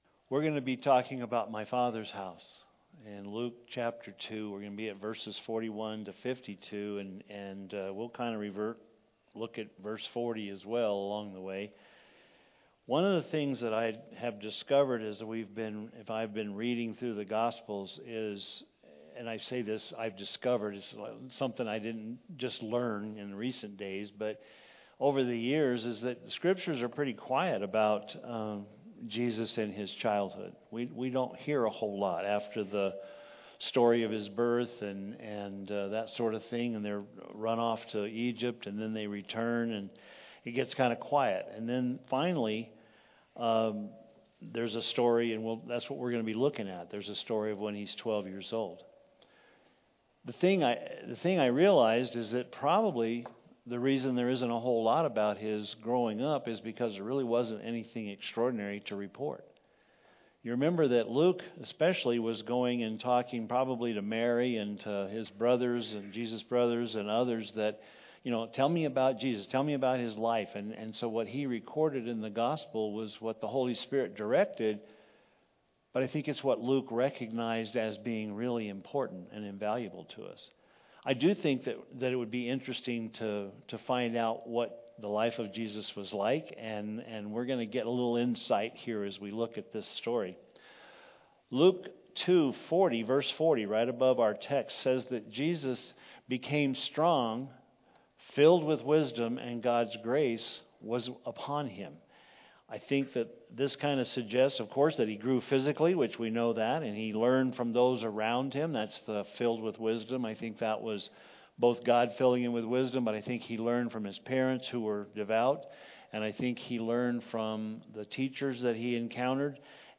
shared the Truth of Jesus in today's sermon.